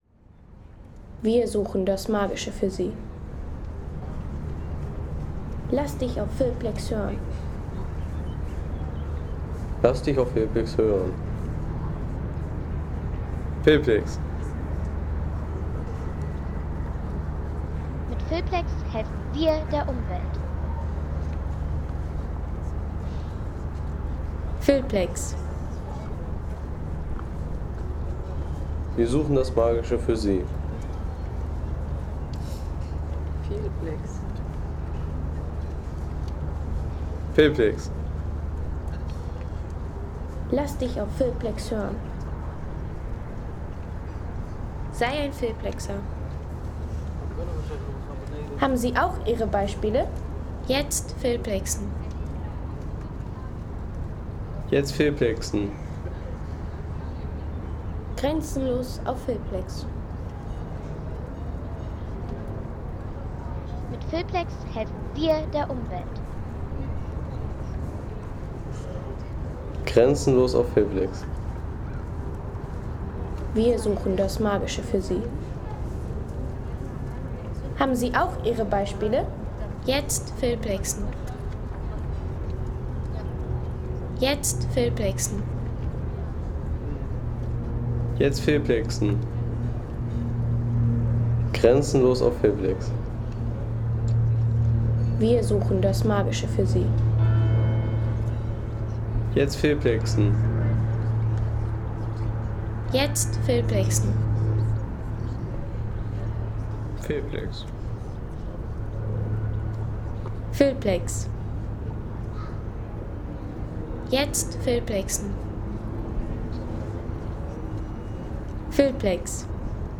Die Reichsburg Cochem | Historische Stadt- und Burgatmosphäre
Authentische Atmosphäre der Reichsburg Cochem mit Burgkulisse, Besuchergeräuschen und Stadtstimmung.
Eine lebendige Burgkulisse aus Cochem mit Besuchergeräuschen und Stadtatmosphäre für Filme, Reisevideos, Dokus und Sound-Postkarten.